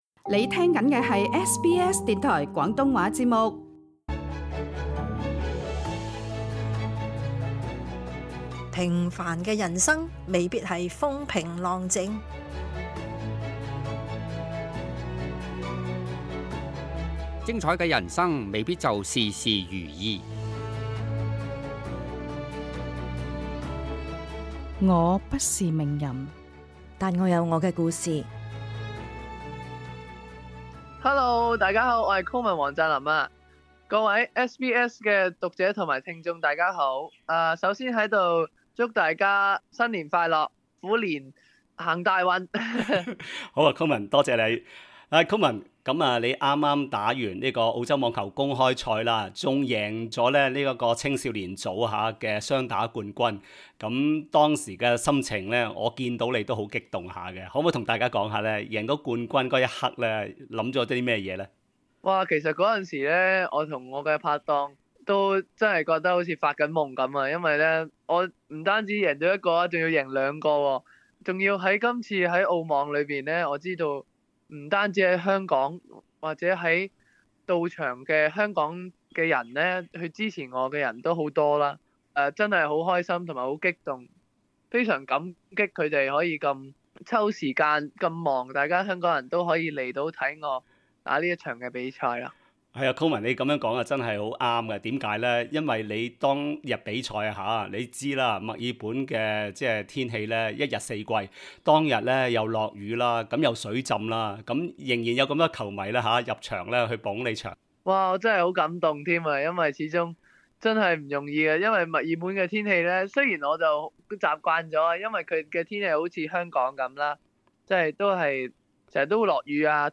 【澳網後專訪】